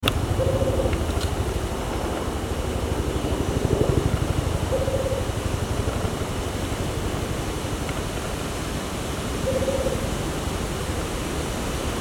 Juruva-verde (Baryphthengus ruficapillus)
Nome em Inglês: Rufous-capped Motmot
Fase da vida: Adulto
Localidade ou área protegida: Parque Nacional Iguazú
Condição: Selvagem
Certeza: Fotografado, Gravado Vocal